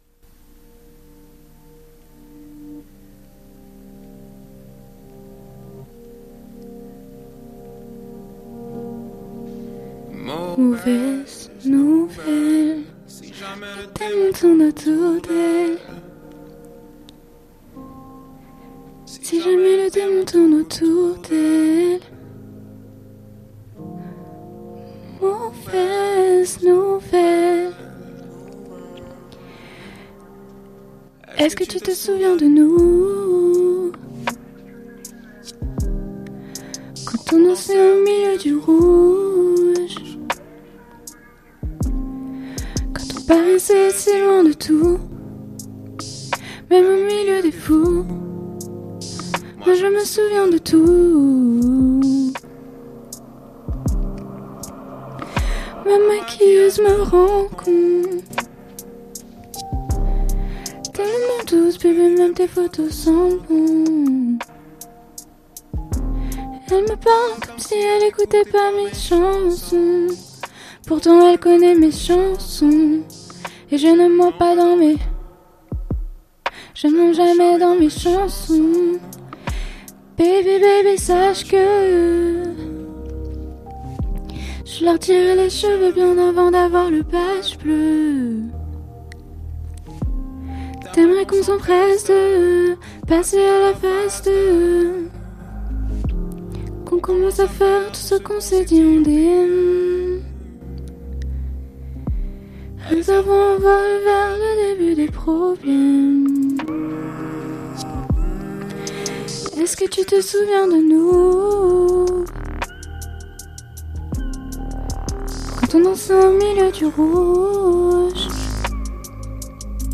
Où la reprise est parfois plus belle que l'originale!...